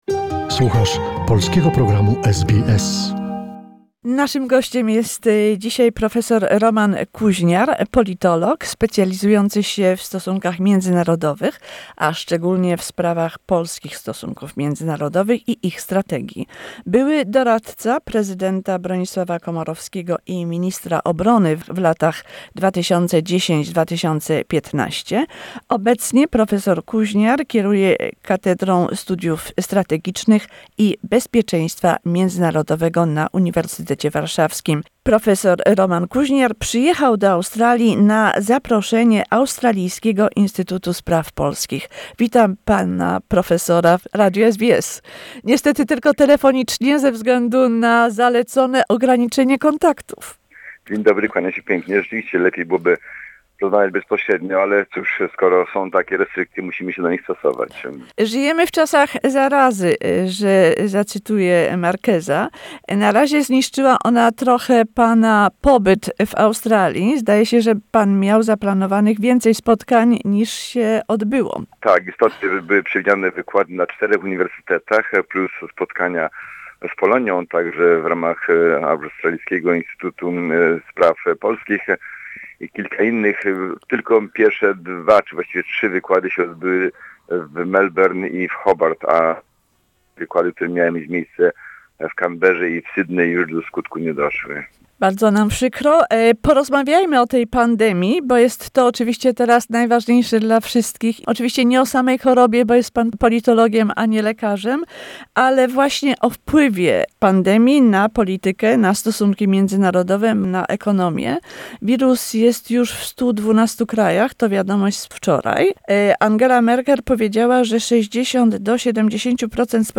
How does the current pandemic affect international relations, does it move countries apart by restoring borders, closing airports or maybe bringing them closer because it is a global problem that requires solidarity? Interview